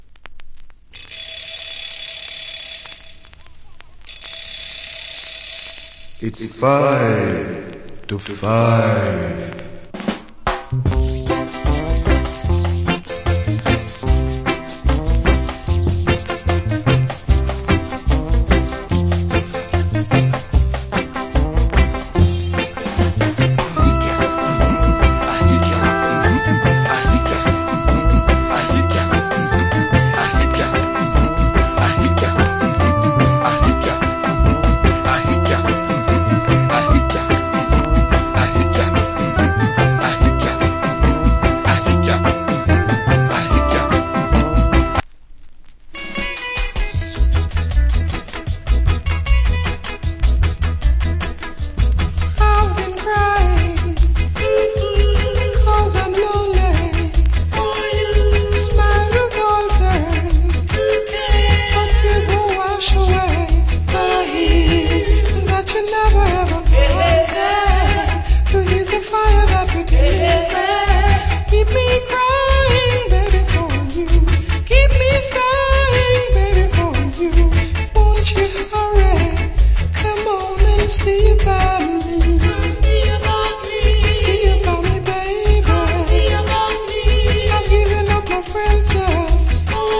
Nice Early Reggae Inst.